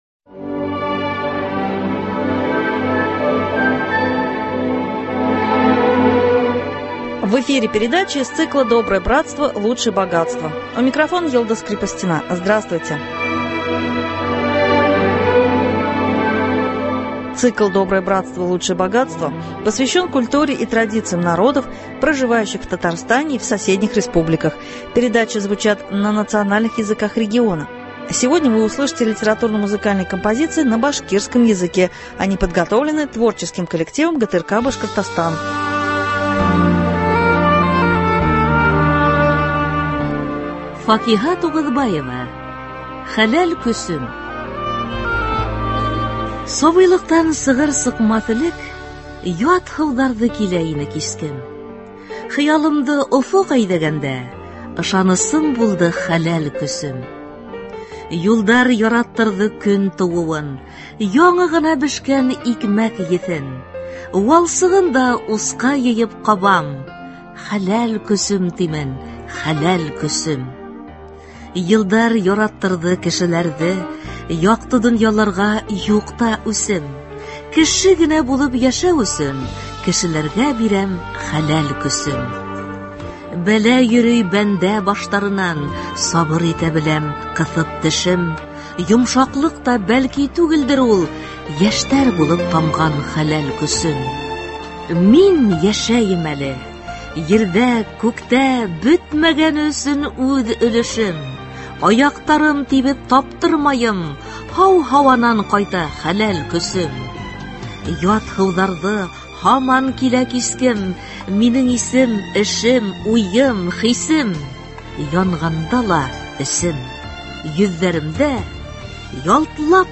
Литературно- музыкальная программа на башкирском языке .